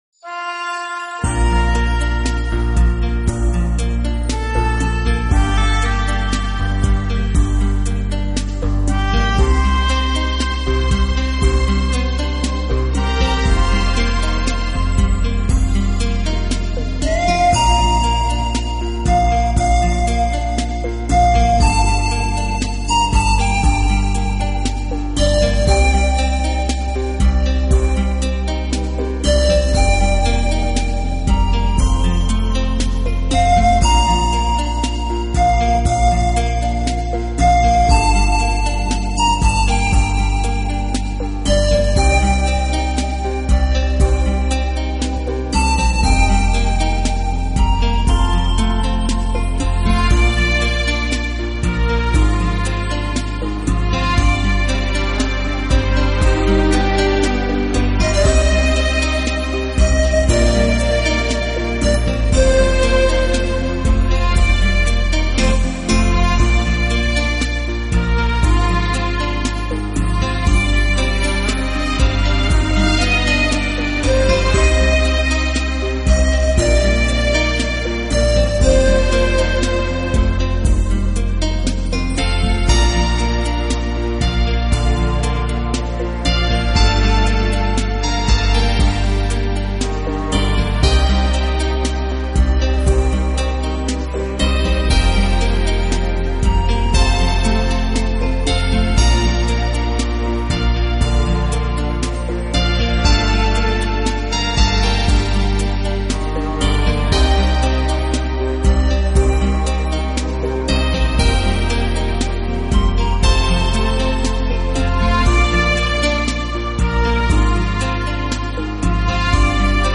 【纯音乐】
最时尚的休闲背景音乐